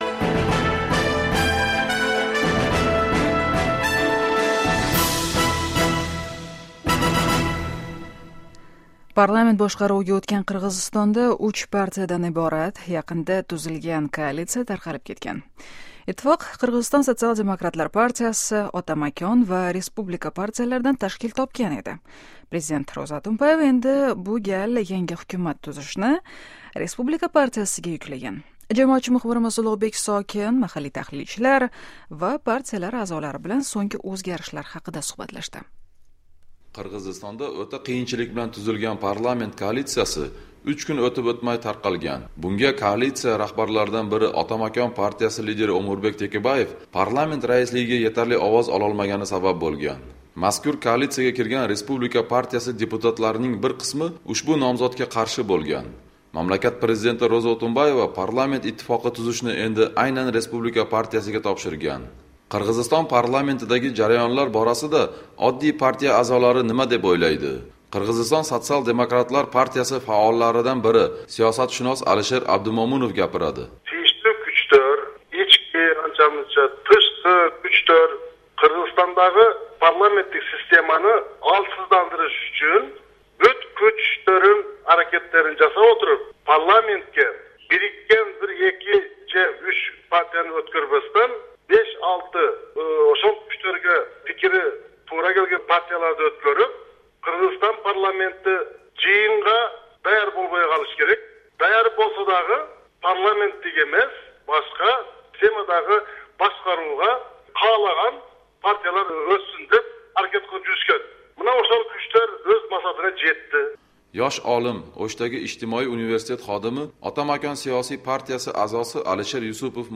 Tahlilchi va siyosatchilar bilan suhbat